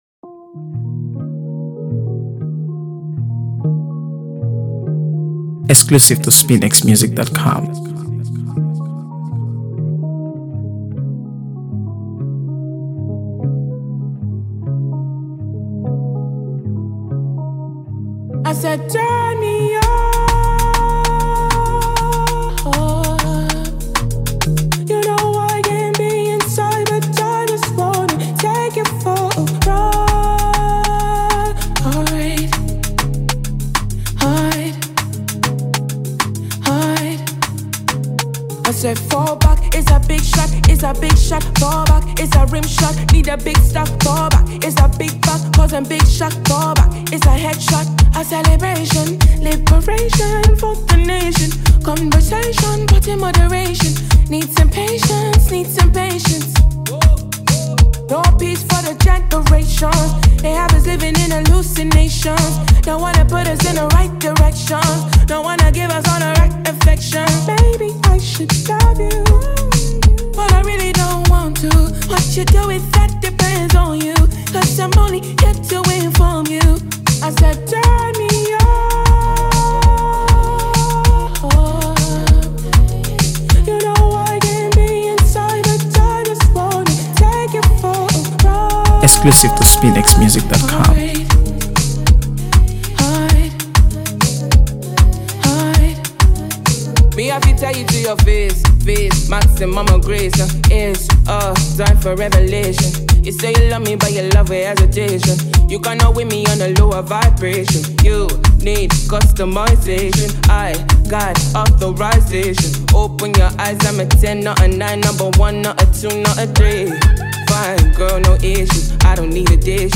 AfroBeats | AfroBeats songs
alt-R&B
Known for her soulful voice and magnetic artistry